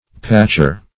Patcher \Patch"er\ (p[a^]ch"[~e]r), n. One who patches or botches.